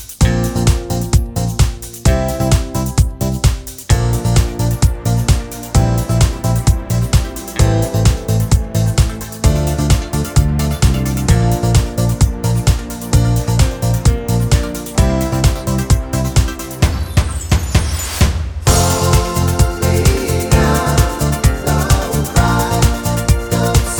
no Backing Vocals Irish 3:36 Buy £1.50